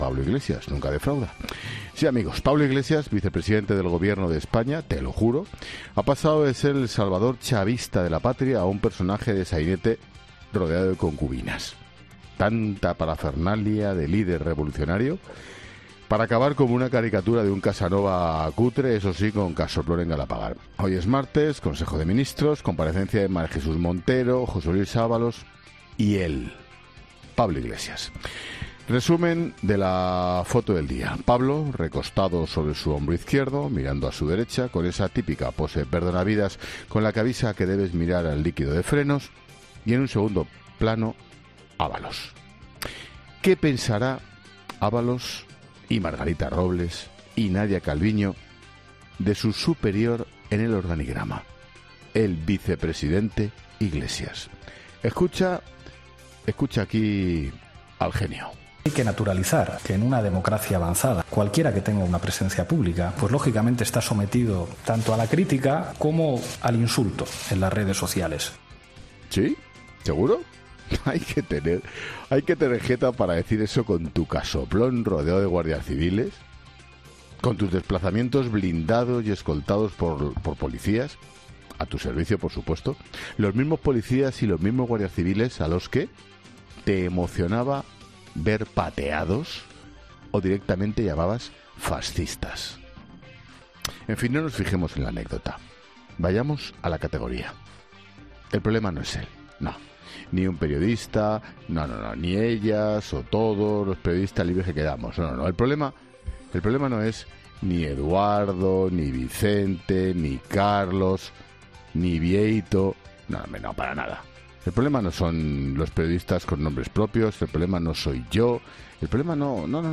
Ángel Expósito ha dedicado buena parte de su monólogo inicial de ‘La Linterna’ de este martes a uno de sus personajes recurrentes: Pablo Iglesias, que “nunca defrauda”.